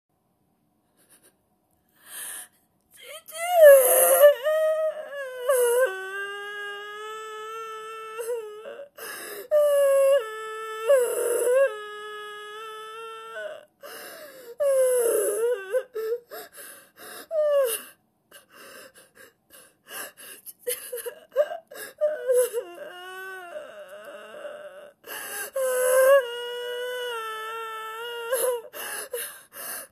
泣き声